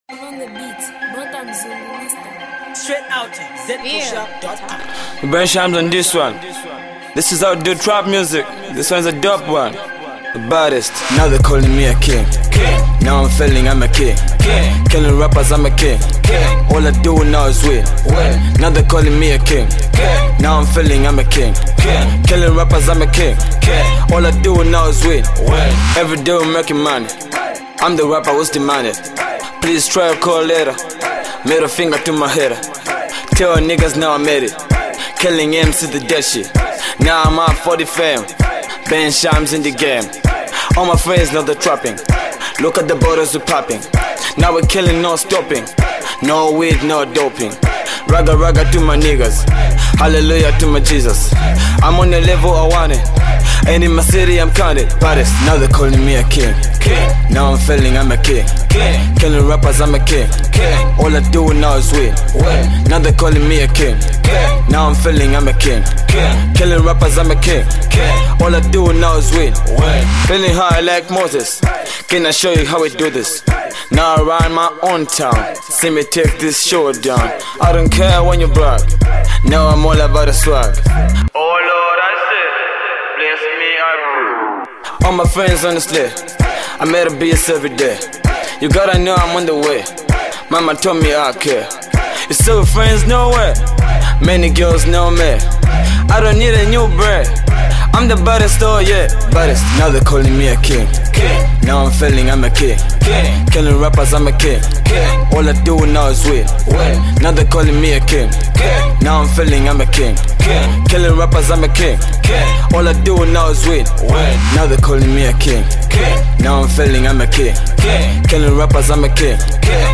trap song